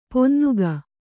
♪ ponnuga